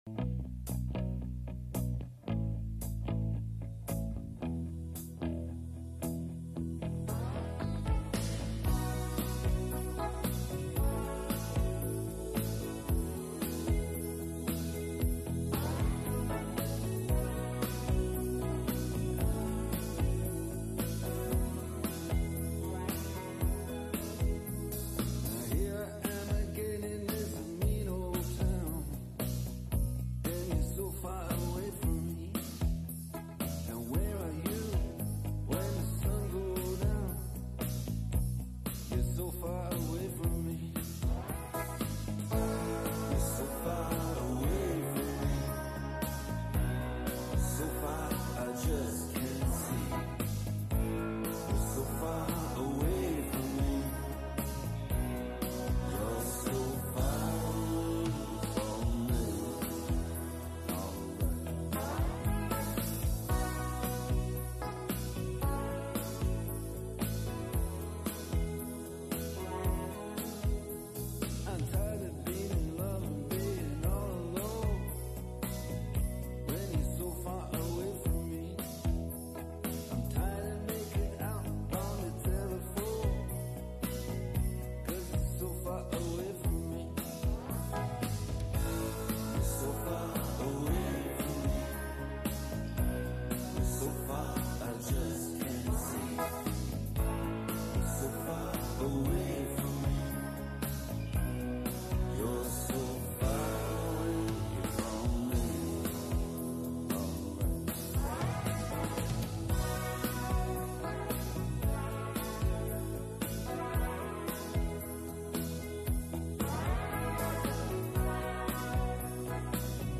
Classic Rock, Blues Rock, Pop Rock